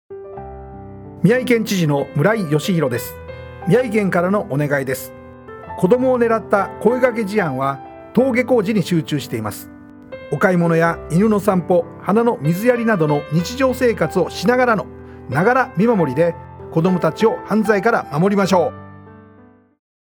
知事メッセージ
ながら見守り活動知事音声メッセージ（MP3：471KB）